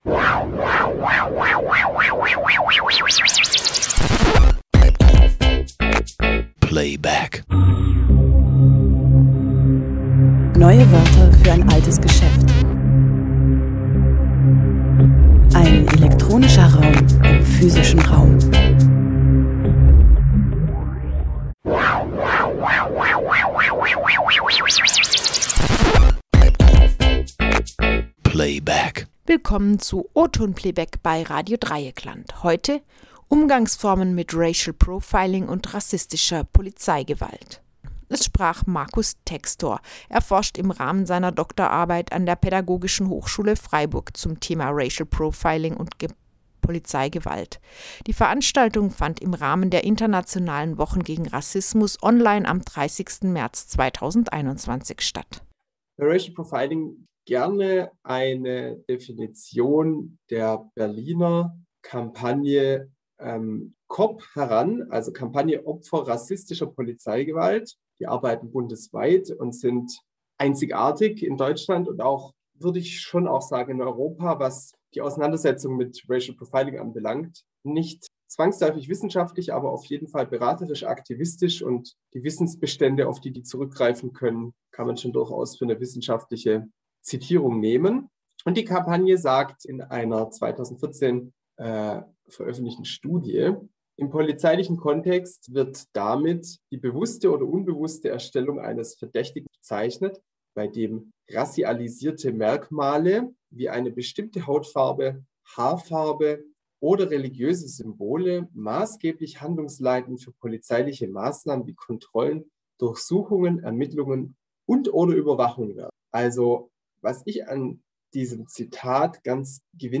Sendezeit: Jeden Freitag im Monat um 19 Uhr (Wiederholung: Dienstag um 14 Uhr)In O-Ton Playback kommen Veranstaltungen und Lesungen nahezu unverkürzt zu Gehör.